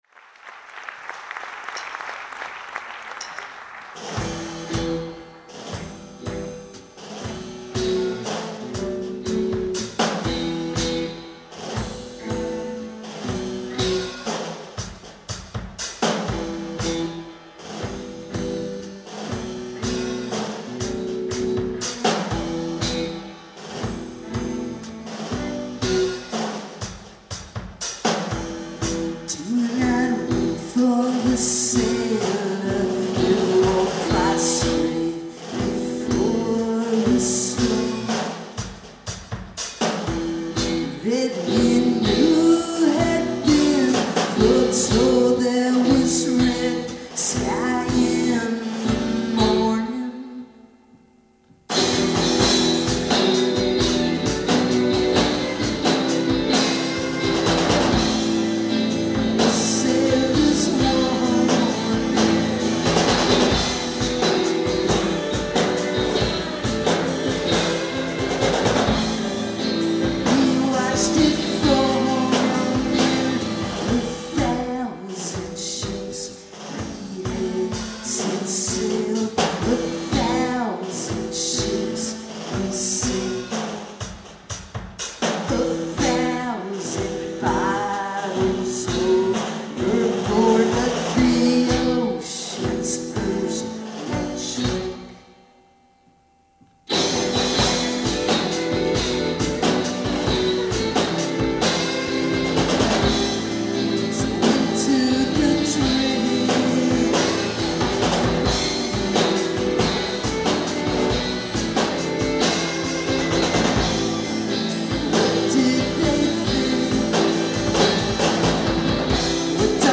Live at the Boston Museum of Fine Arts